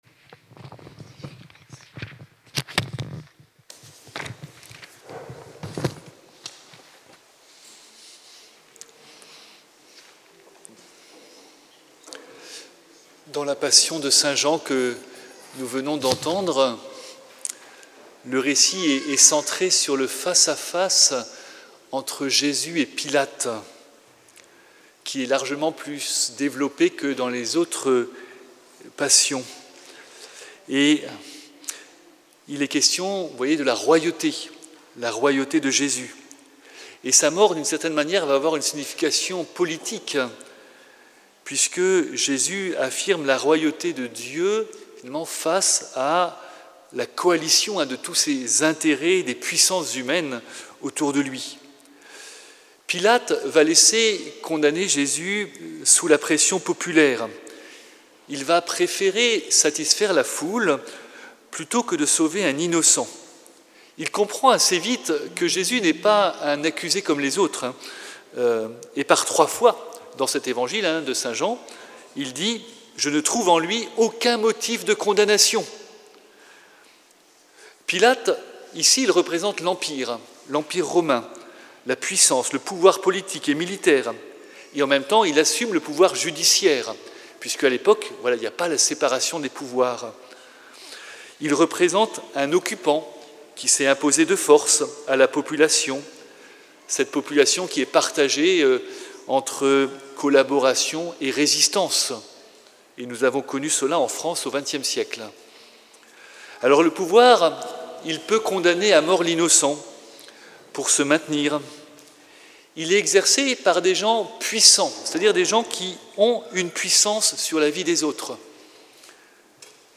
Audio : 2026-04-03 Homélie du Vendredi Saint 3 avril 2026